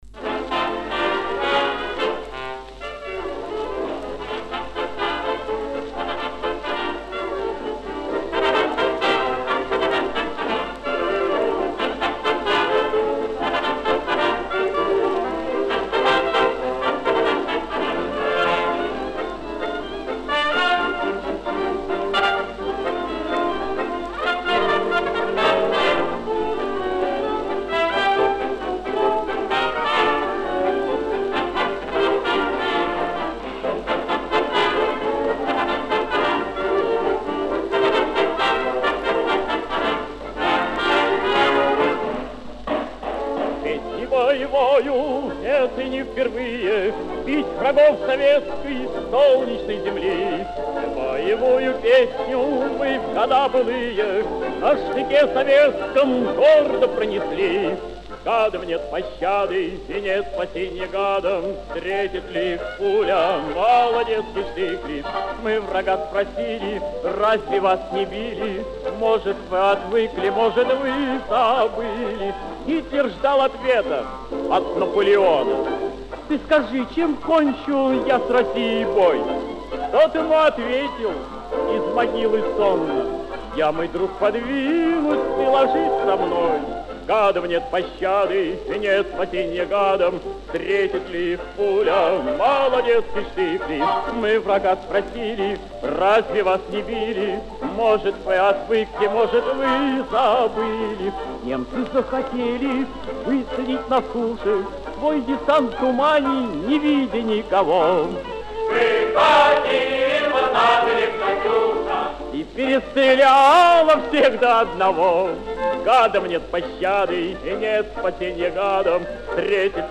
Повышение качества: без металлического фона.